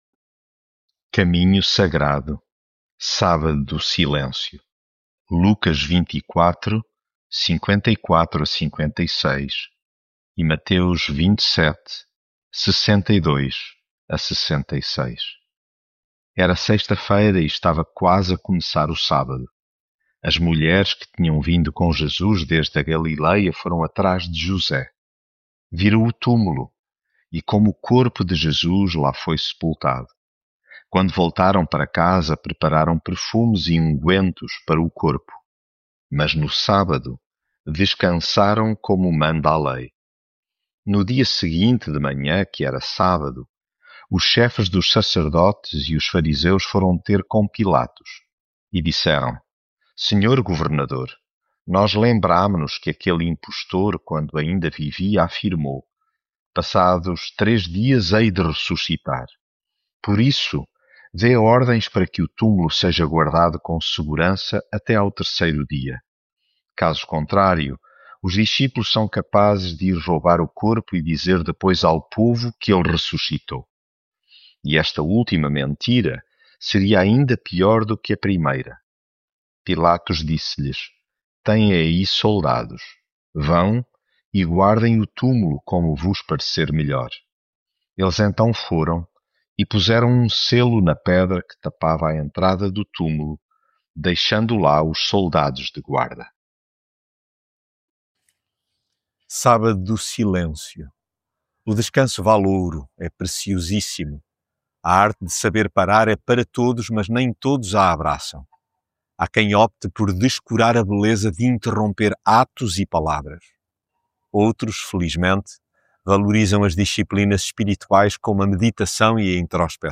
Devocional